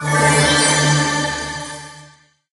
heal01.ogg